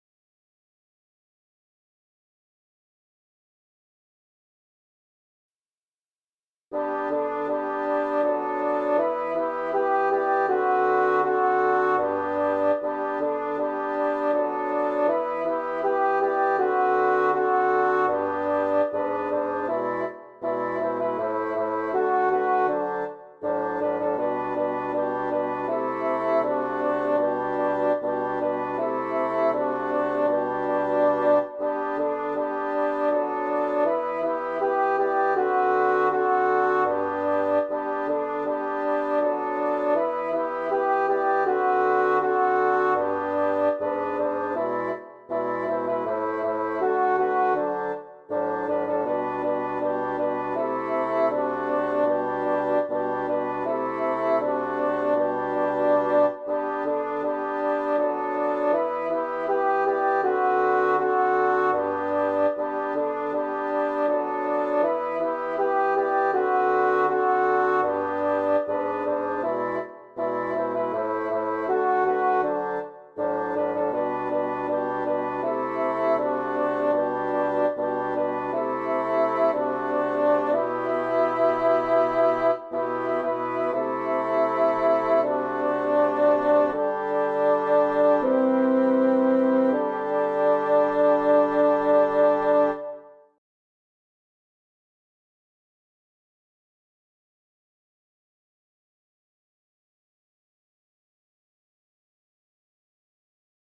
MP3 version instrumentale (les audios sont téléchargeables)
Alto